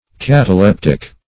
Cataleptic \Cat`a*lep"tic\, a. [Gr. katalhptiko`s.]